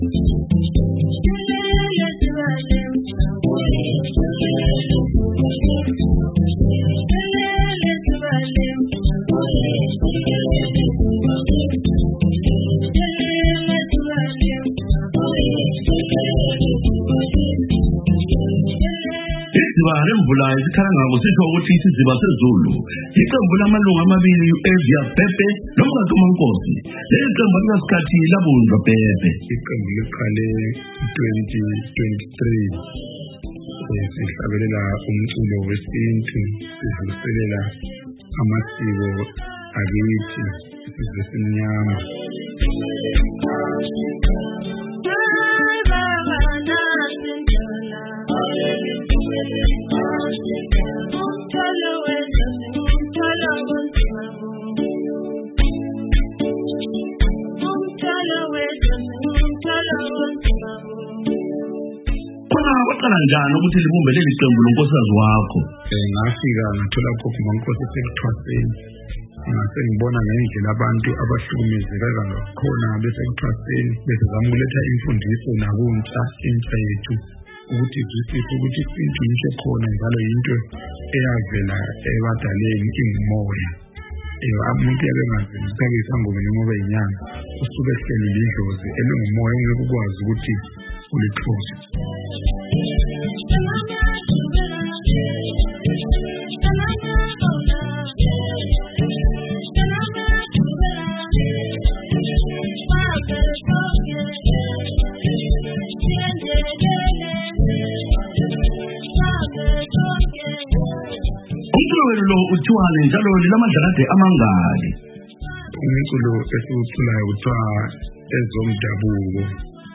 Kuliviki kuhlelo Woza Friday sileqembu elizibiza Dziba leMvula elicula ingoma zomdabuko likoBulawayo libuye lisebenze lase South Africa.